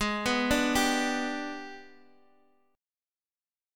G/Ab Chord
G-Major-Ab-x,x,6,4,3,3-8.m4a